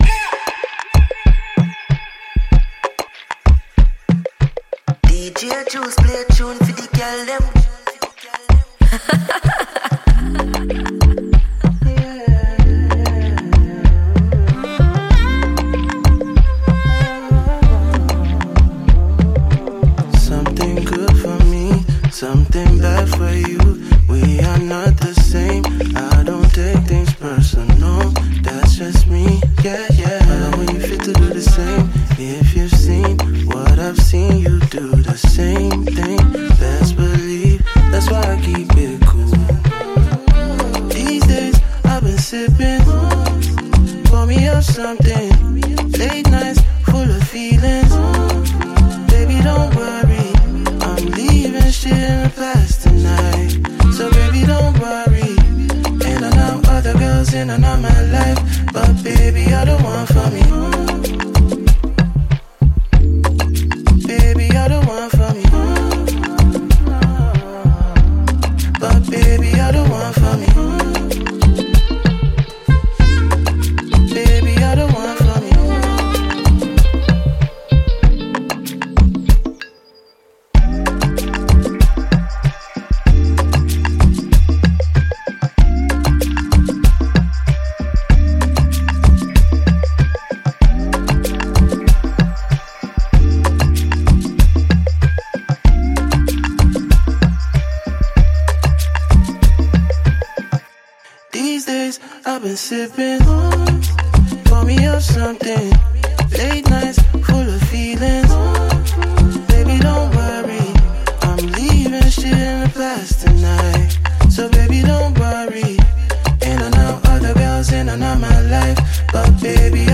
a versatile Nigerian neo-soul singer